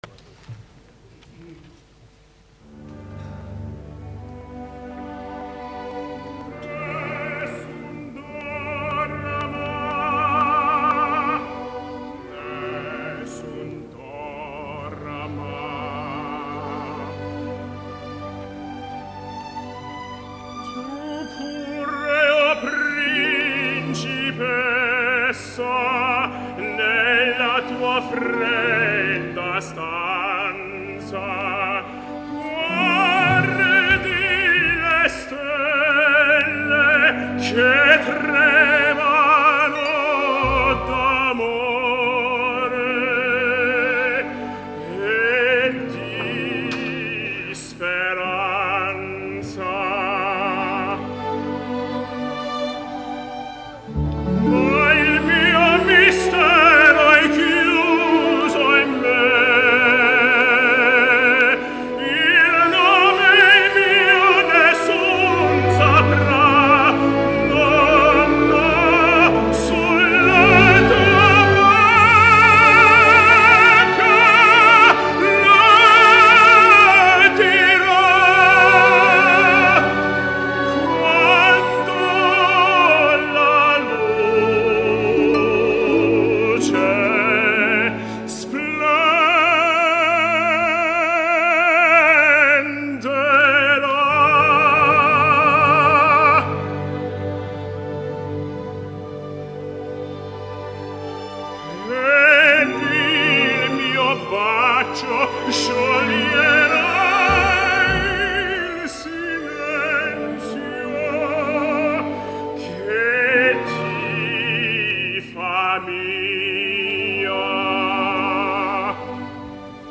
L’escoltarem en àries prou conegudes, del gran repertori i en una gravació provinent d’un concert en directa, per tant podreu apreciar les virtuts d’una veu in un cantant, de la mateixa manera que evidenciareu que no tot està assolit i que encara queden coses per polir.
Tots els fragments provenen d’un concert celebrat el dia 9 de gener de 2013 a Jerusalem, l’orquestra està dirigida per Frédéric Chaslin